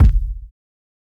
Kick (7).wav